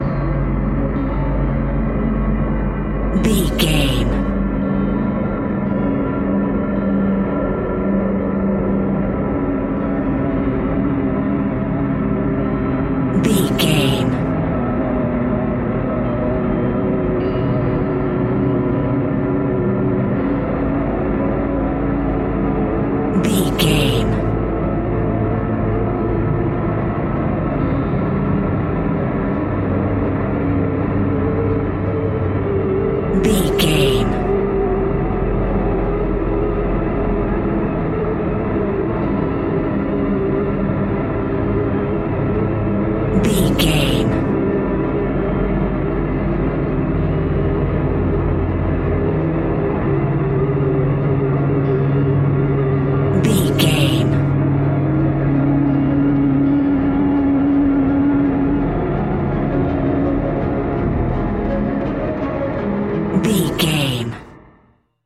Ionian/Major
C♯
dark ambient
EBM
drone
synths
Krautrock